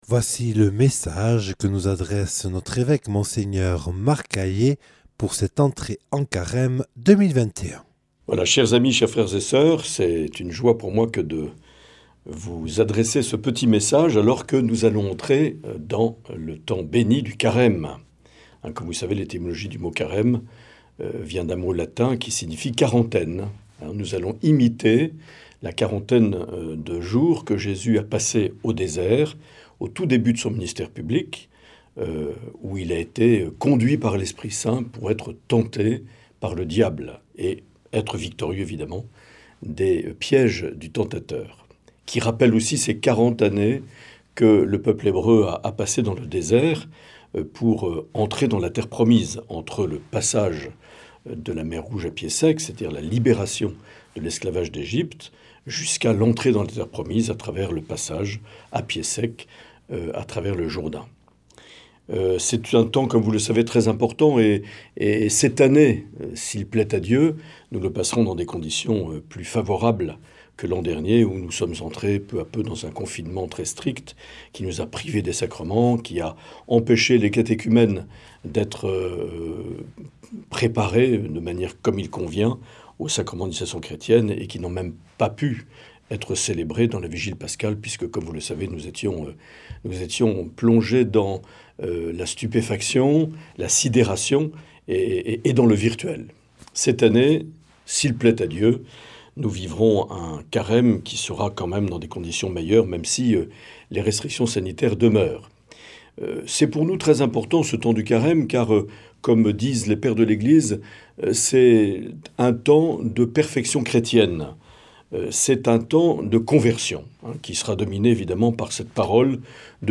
Mgr Marc Aillet nous adresse ce message à l’occasion de l’entrée en Carême 2021.